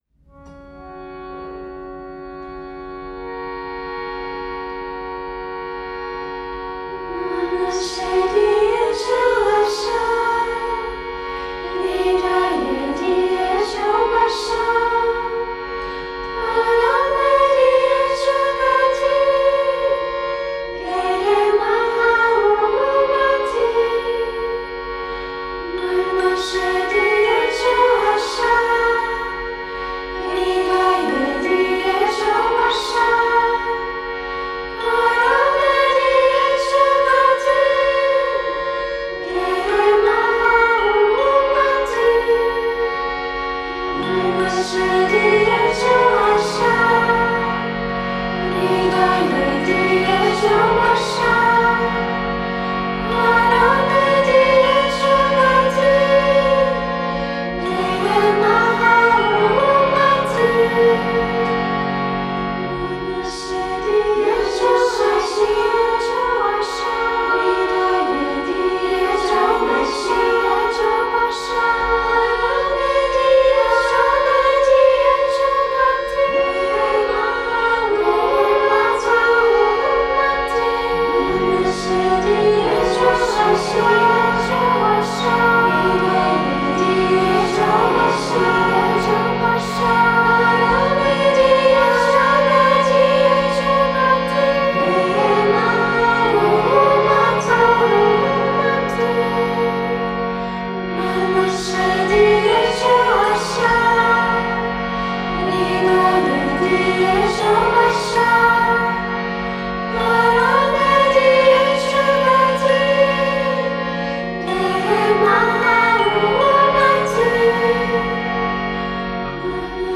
Glockenspiel